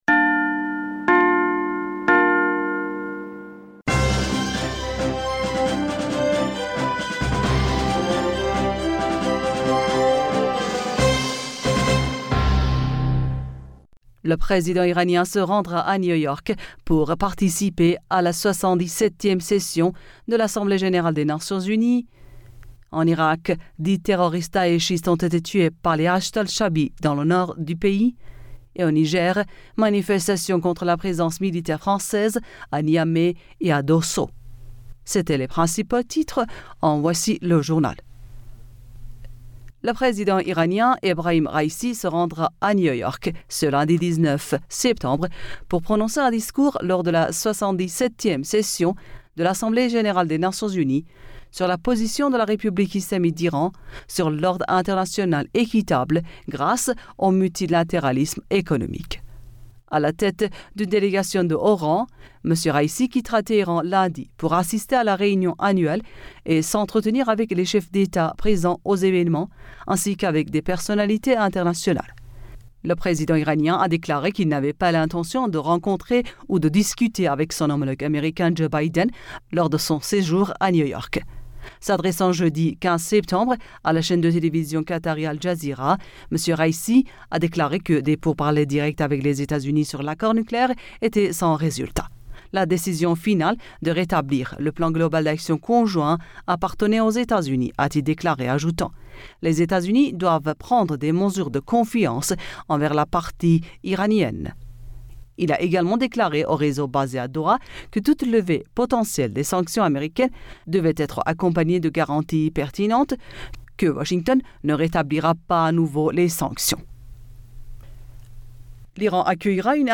Bulletin d'information Du 19 Septembre